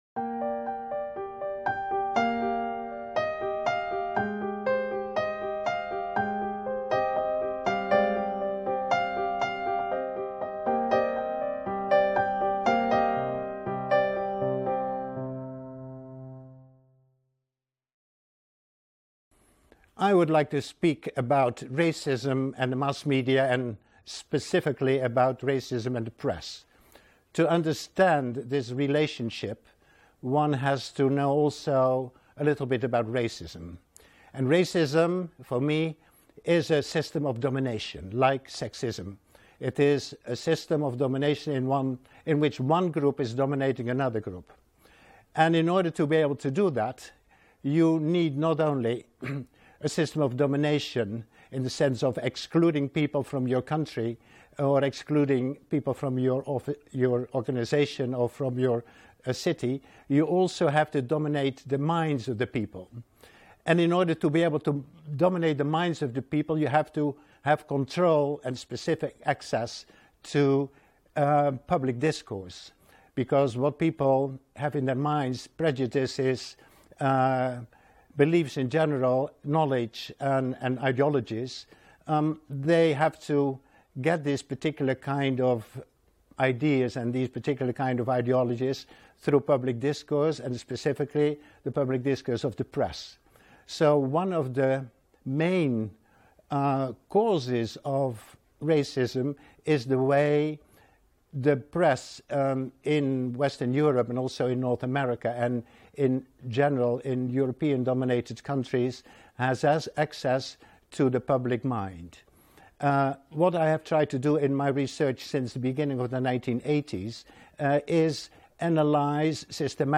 Le rôle des médias - Une intervention de Teun Van Dijk | Canal U